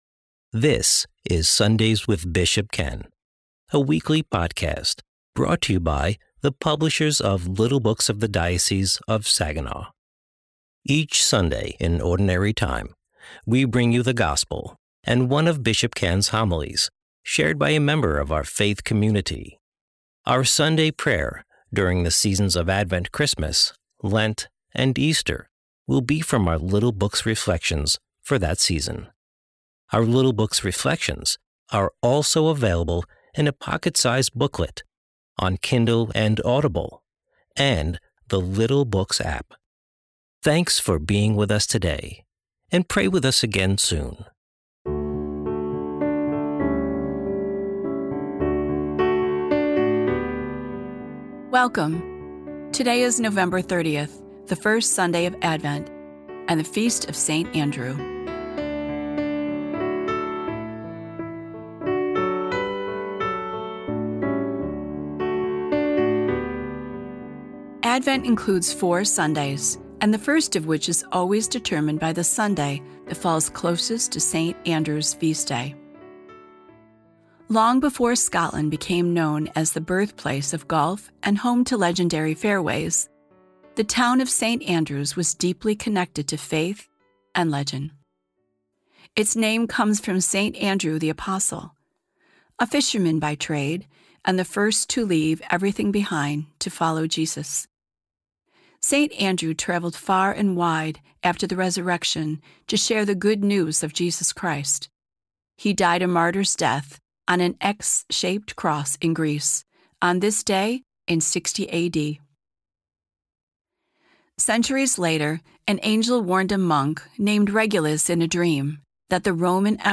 Today's episode of Sunday's with Bishop Ken is the first Sunday of Advent's reading from The Little Blue Book: Advent and Christmas 2025.